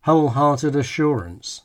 Furthermore, in a phrase like wholehearted assurance, whole- may well be more prominent than -hearted (thanks to the phenomenon known by names such as ‘the rhythm rule’ or ‘stress shift’):